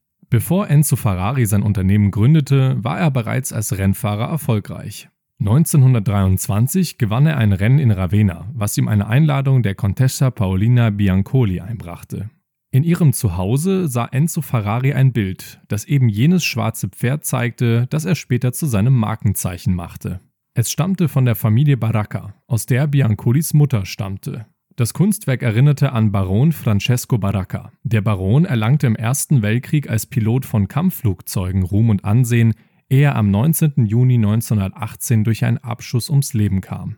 sehr variabel, markant
Doku
Spanish (Spain)